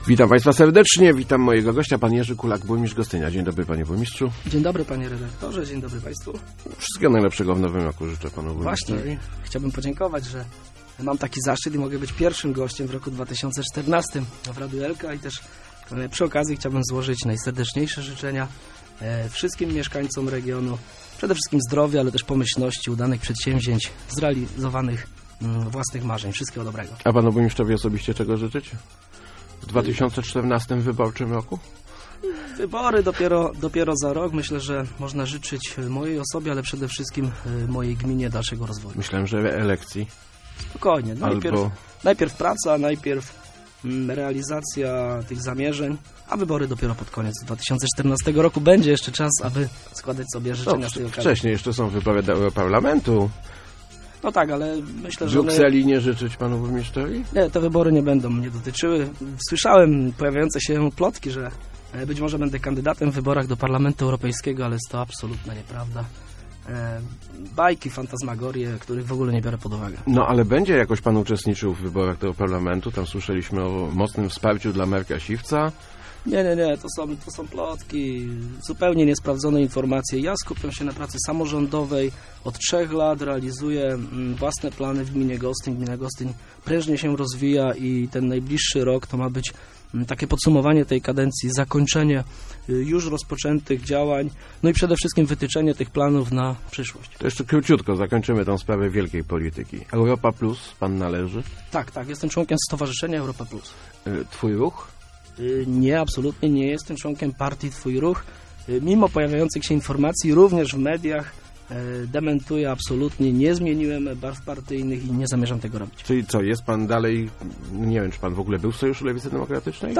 Czas pokazał, że decyzja o rewitalizacji Rynku była słuszna - powiedział w Rozmowach Elki burmistrz Gostynia Jerzy Kulak. Jak podkreślił, zwiększa się ilość podmiotów gospodarczych w centrum miasta, a co za tym idzie wpływy do miejskiej kasy.